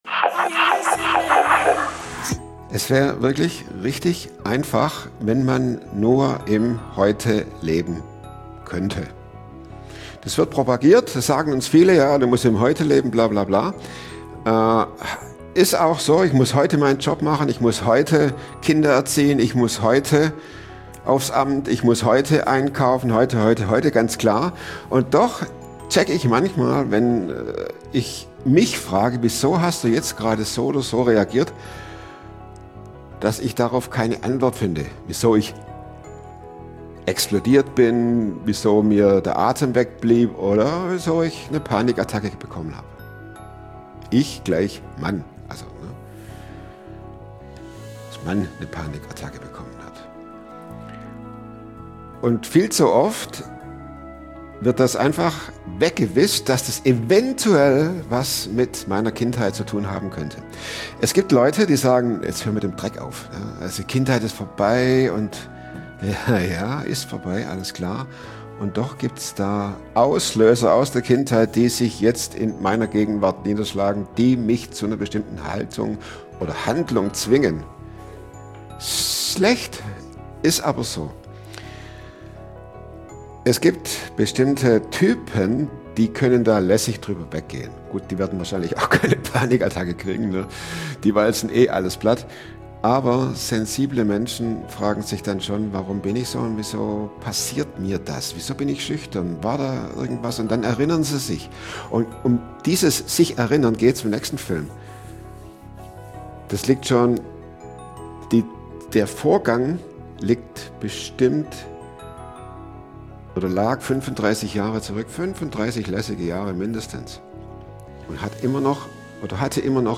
superfromm kommt wöchentlich. superfromm ist ein Mix aus Drama, Comedy und Alltagszoff. Vor dem Mikro ist im Atelier.
Was wie ein Krimi beginnt, ist ein tiefes, ehrliches Gespräch über Traumata, Glauben und die Kraft, Dinge nicht mehr zu verstecken.